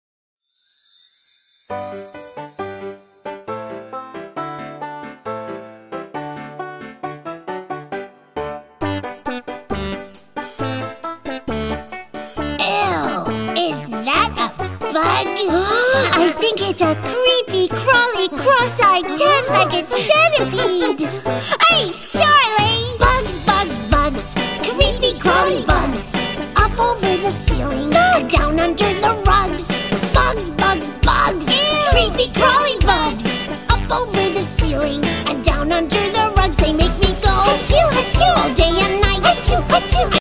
Funny Cartoon Videos And Nursery Rhymes